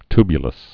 (tbyə-ləs, ty-)